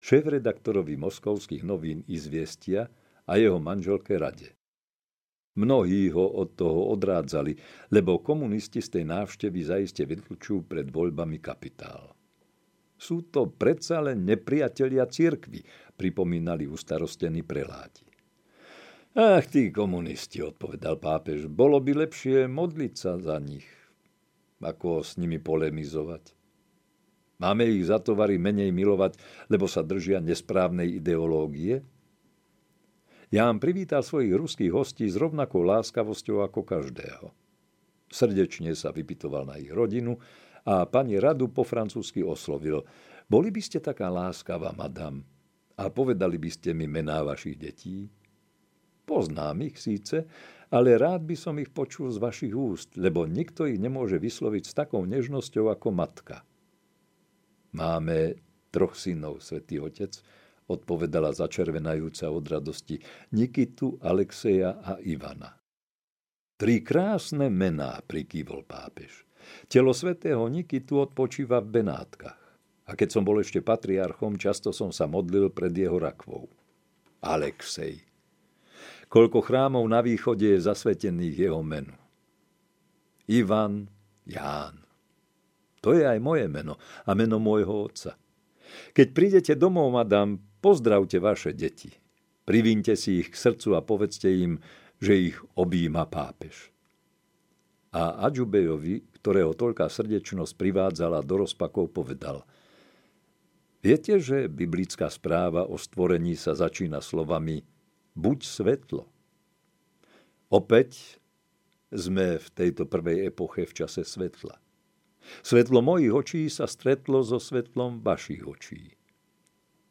Ján XXIII. Pastier sveta audiokniha
Ukázka z knihy
jan-xxiii-pastier-sveta-audiokniha